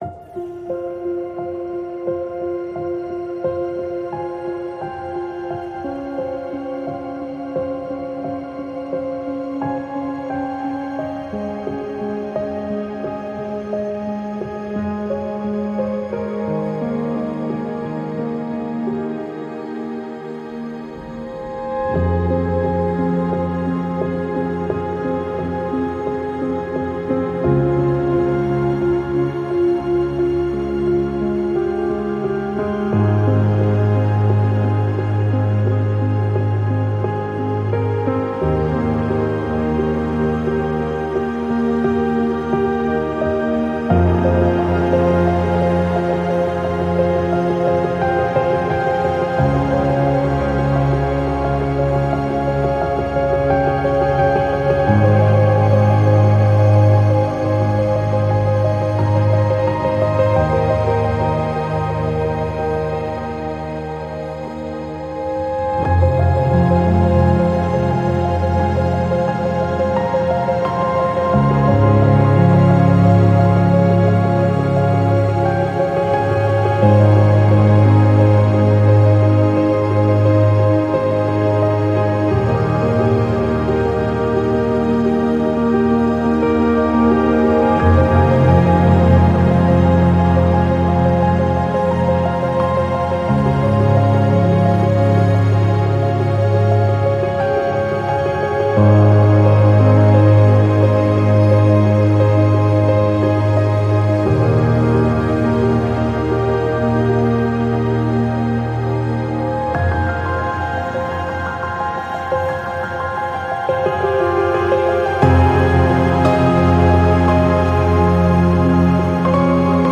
Acoustic
ambient piano